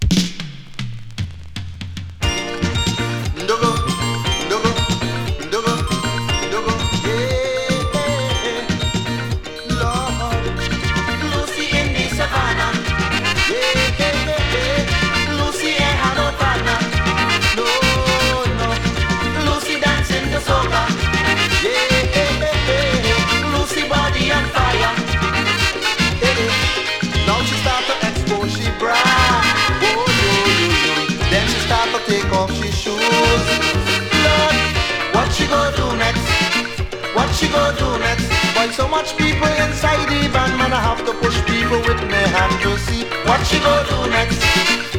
ひたすらに楽しさ溢れてます。
World, Soca　France　12inchレコード　33rpm　Stereo